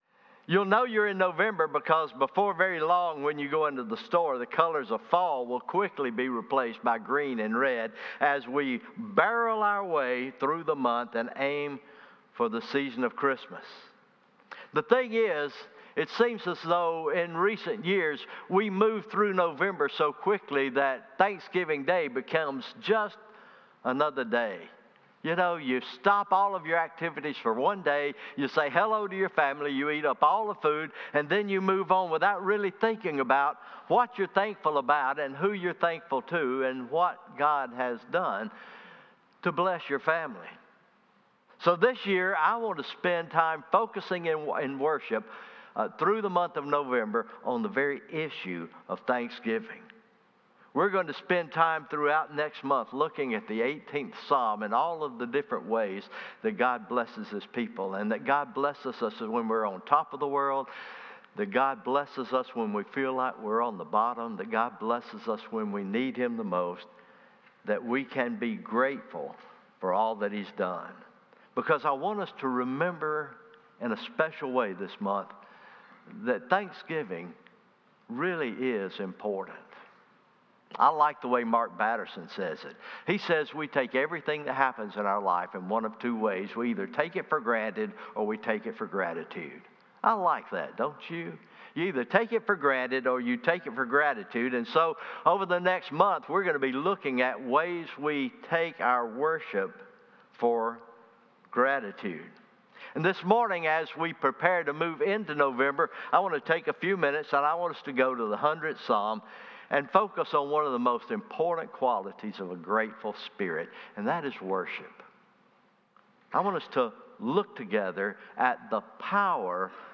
Morning Worship